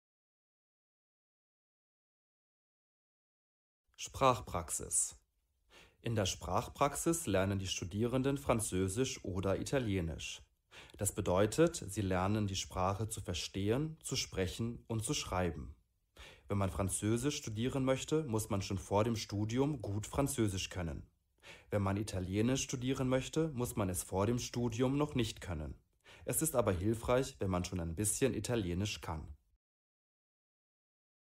Hörversion der Seite.